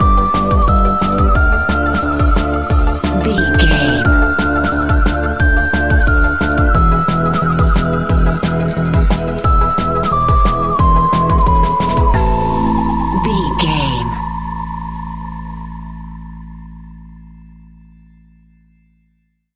Aeolian/Minor
Fast
groovy
uplifting
futuristic
driving
energetic
repetitive
drum machine
synthesiser
organ
break beat
electronic
sub bass
synth leads
synth bass